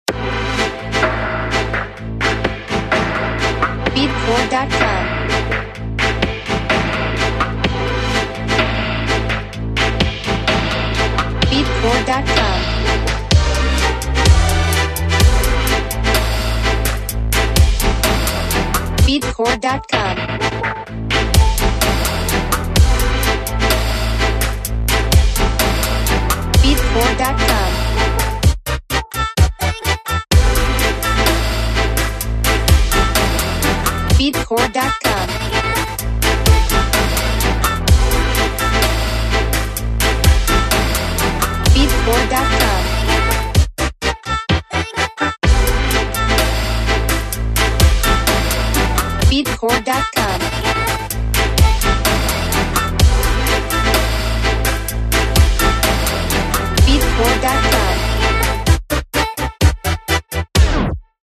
Genre: Future Bass
Time Signature: 4/4
Instruments: Synthesizer Strings Vocal